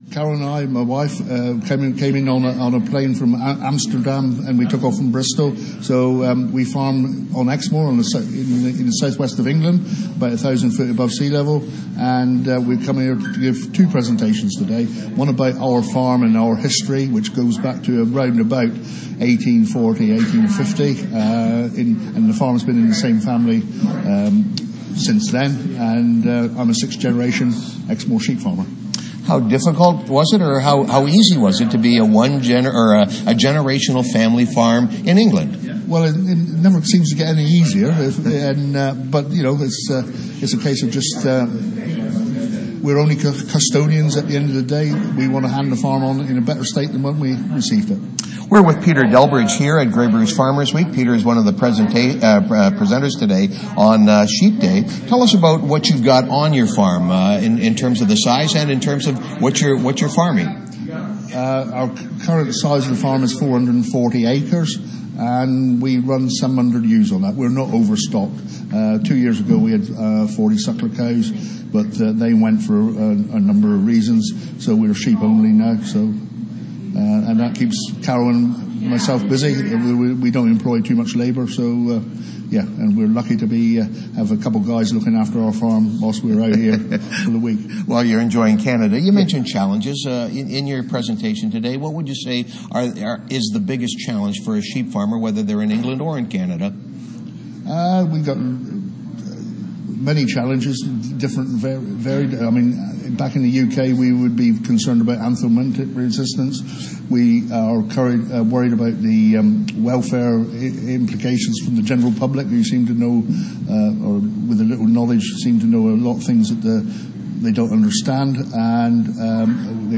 Sheep Day at Grey Bruce Farmers Week. Interview